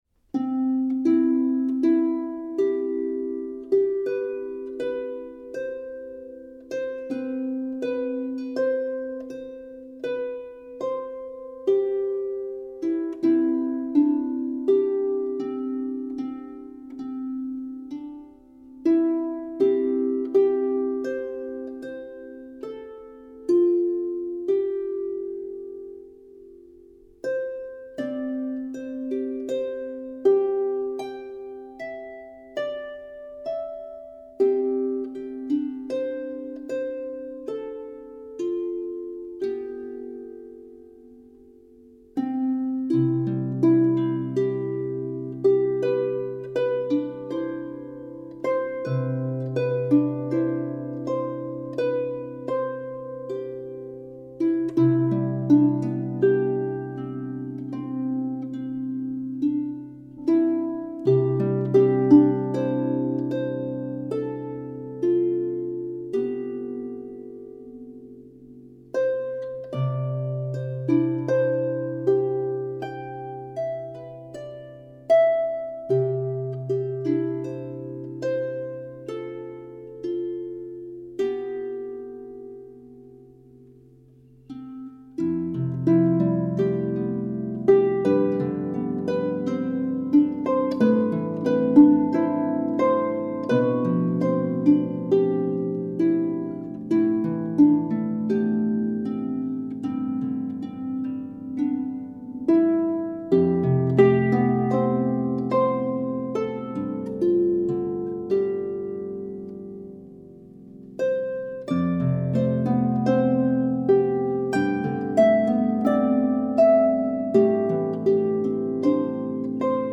for solo lever or pedal harp
Christmas carol is both haunting and contemplative.